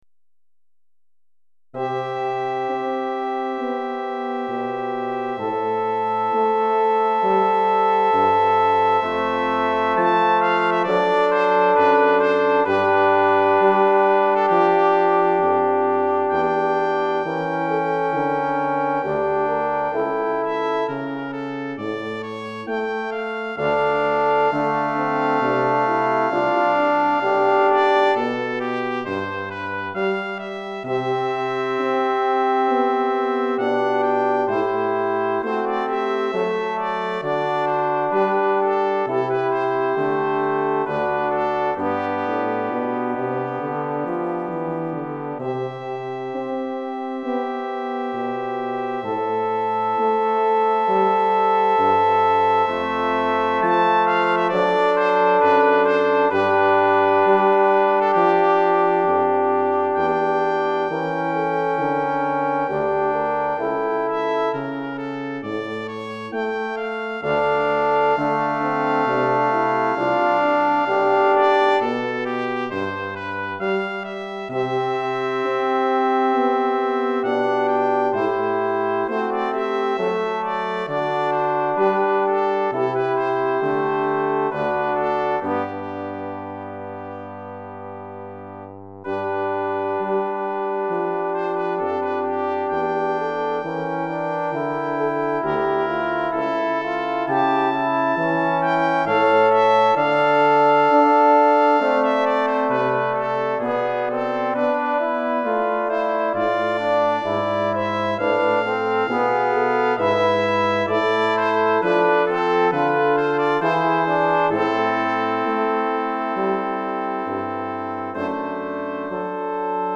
Quatuor de Cuivres